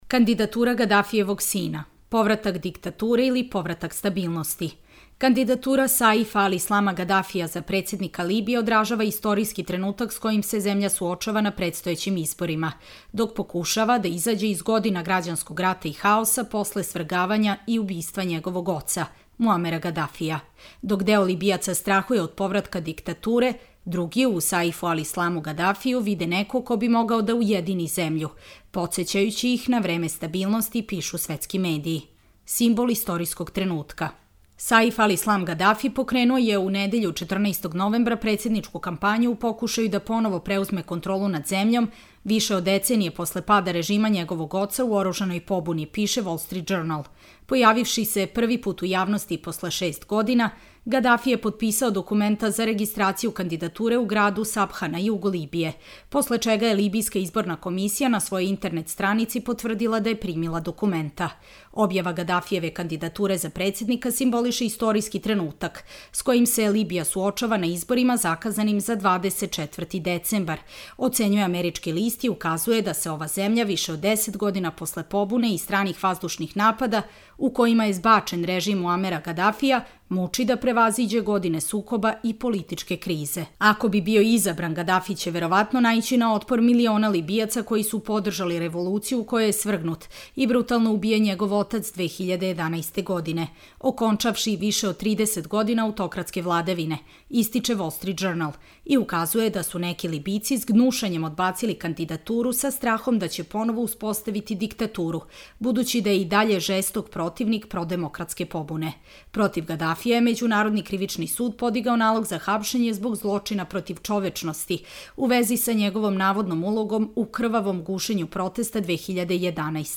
Čitamo vam: Kandidatura Gadafijevog sina – povratak diktature ili povratak stabilnosti